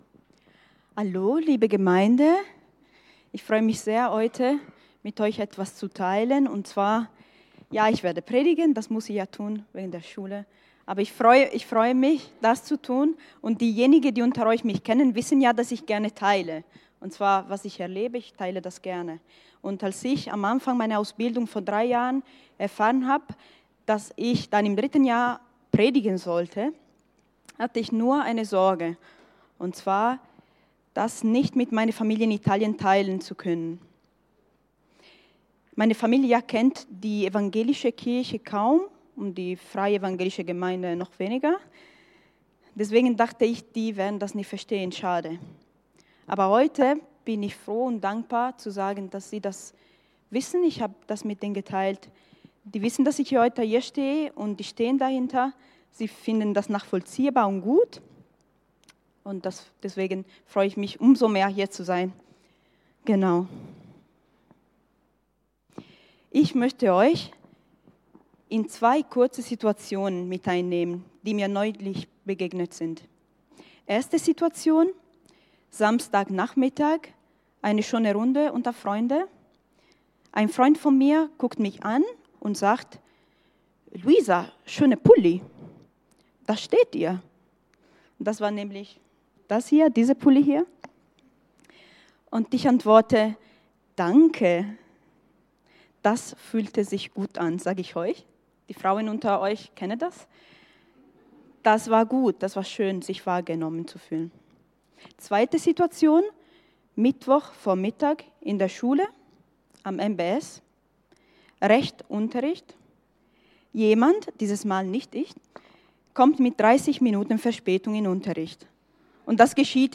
| Marburger Predigten